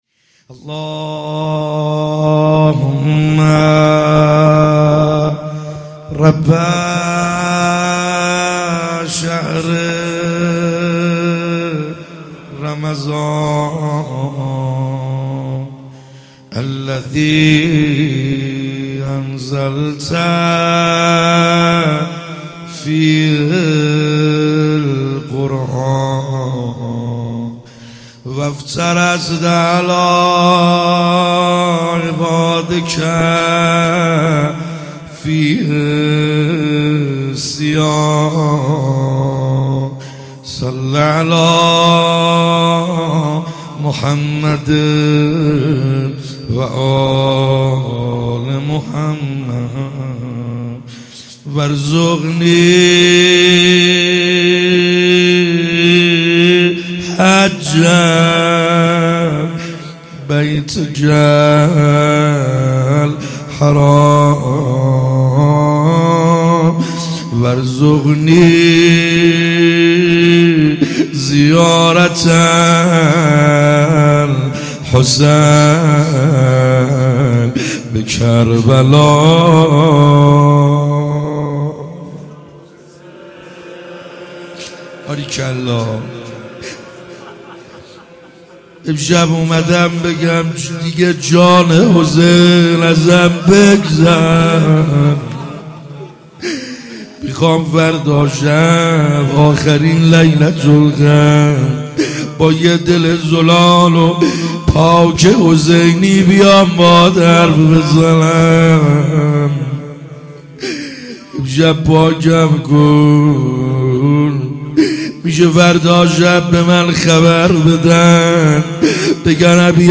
خیمه گاه - بیرق معظم محبین حضرت صاحب الزمان(عج) - روضه